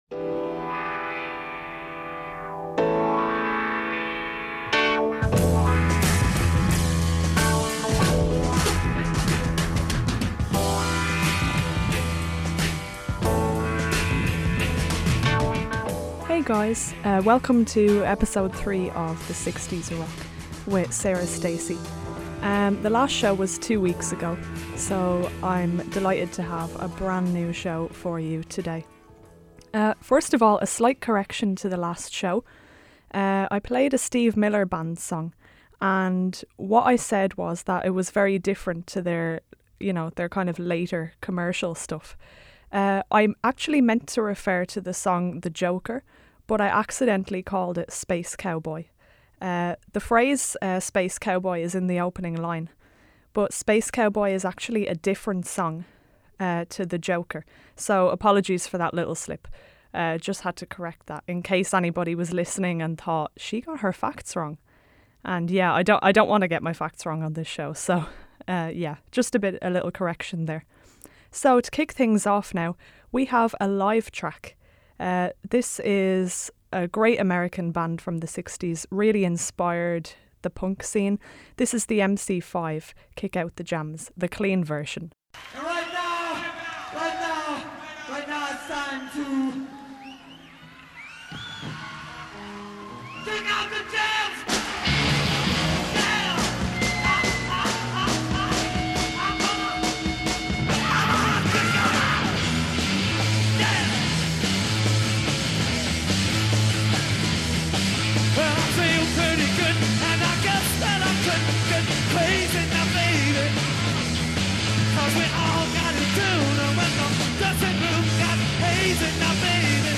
Your next dose of great 60s rock music is right here.